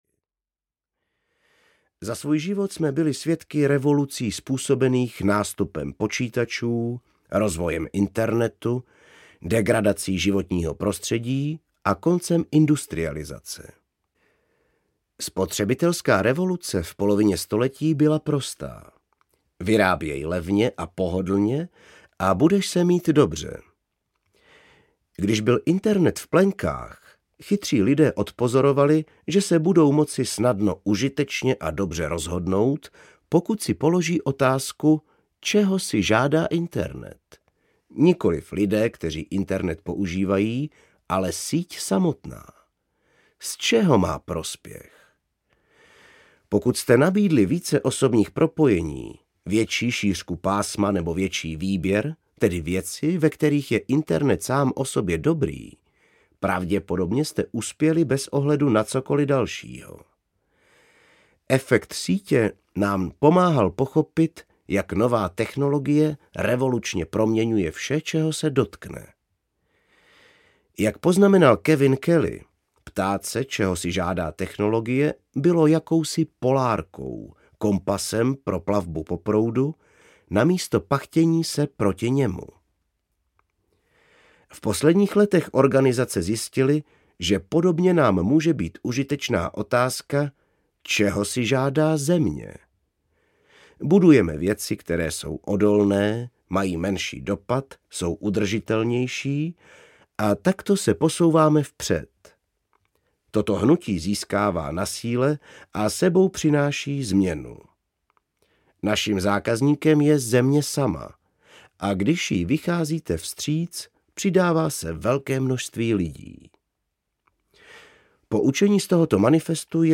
Smysluplná práce audiokniha
Ukázka z knihy